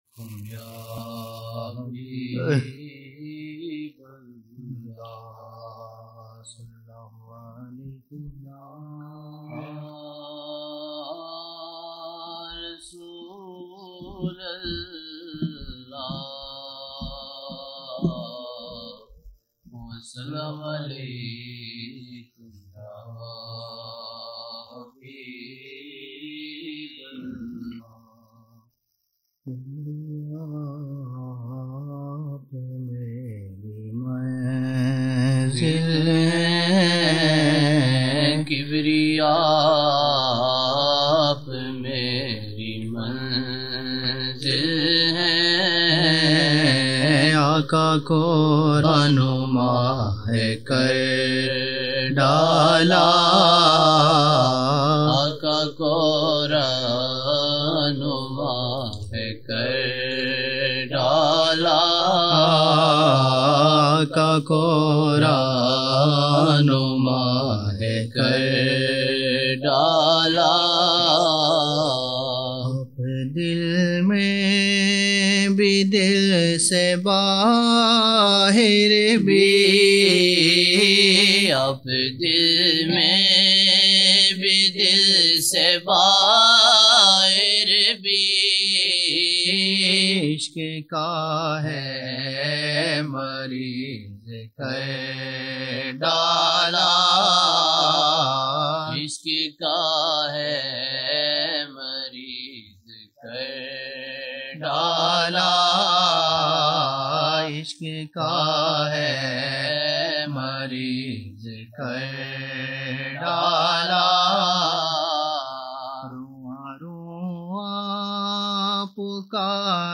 21 November 1999 - Maghrib mehfil (13 Shaban 1420)